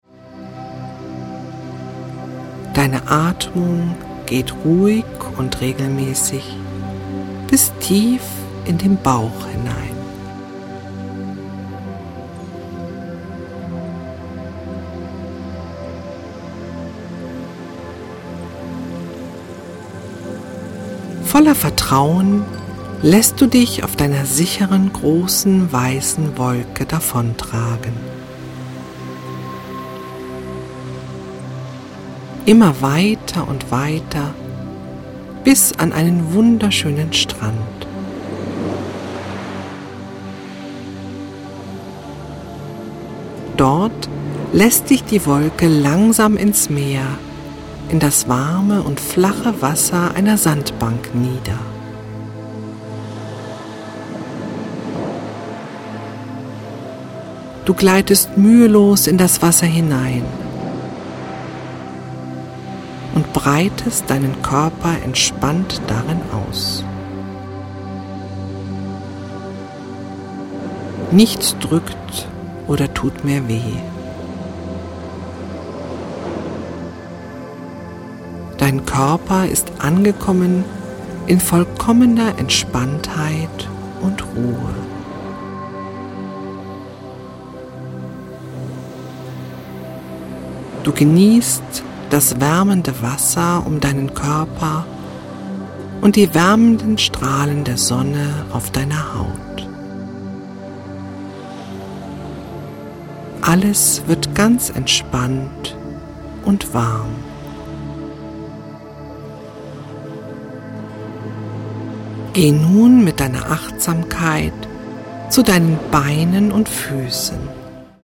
Atemtechniken; Progressive Muskelentspannung; Autogenes Training; entspannende, schmerzlindernde Phantasiereise; regenerierende Entspannungsmusik in 432 HZ
weibliche Stimme